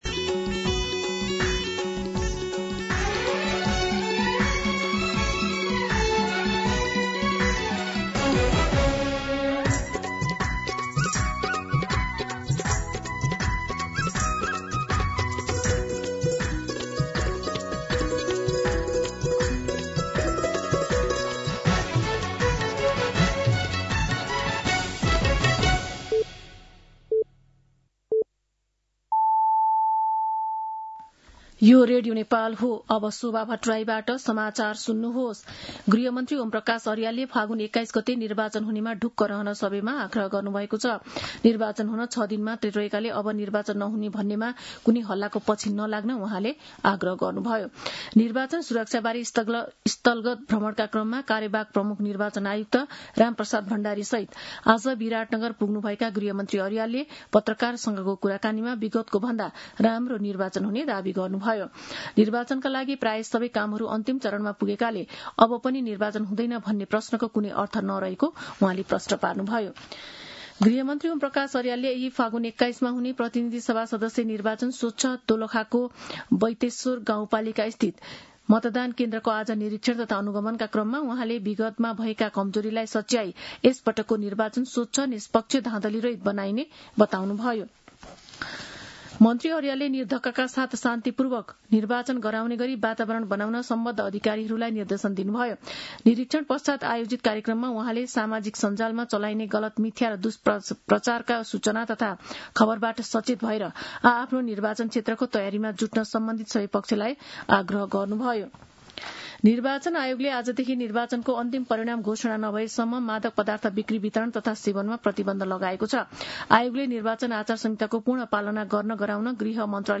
दिउँसो ४ बजेको नेपाली समाचार : १५ फागुन , २०८२